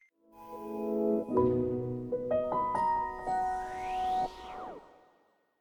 PowerOn.ogg